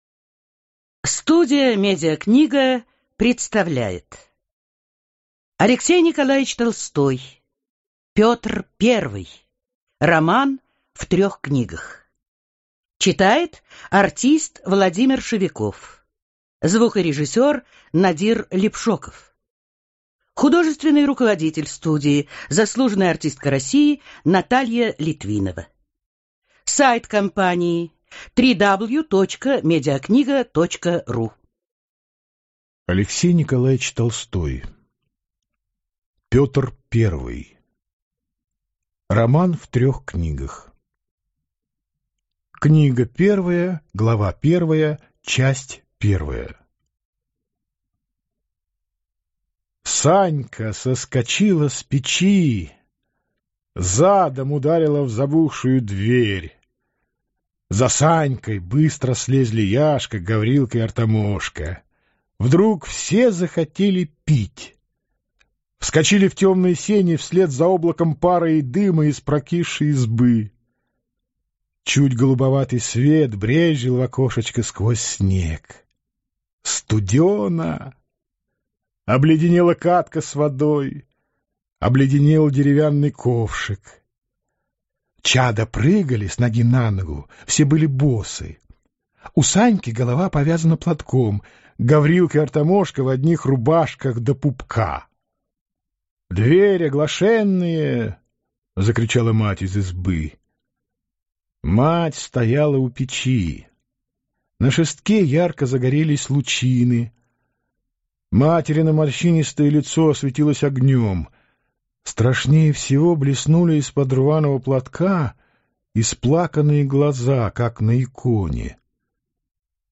Аудиокнига Петр Первый | Библиотека аудиокниг